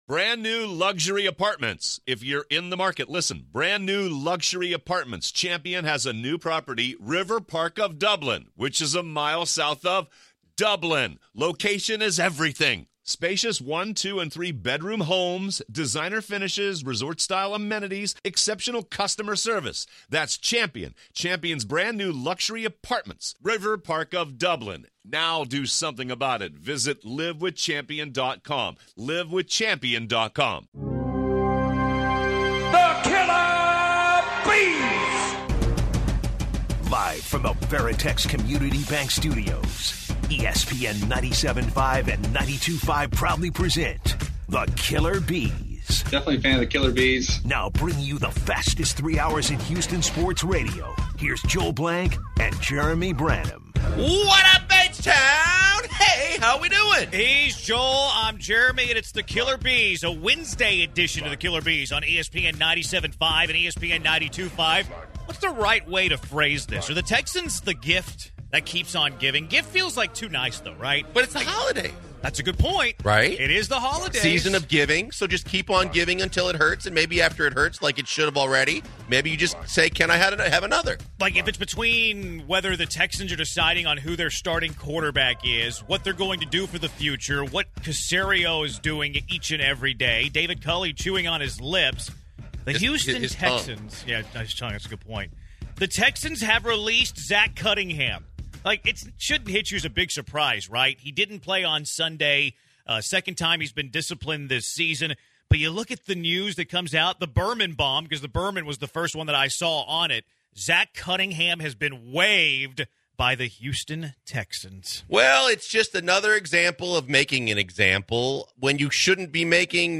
Bottom of the hour former Rockets coach and NBA great Kevin McHale calls in to talk about the teams current hot streak and they wrap up the hour asking how much more should Texans fans put up with with Cal McNair as owner.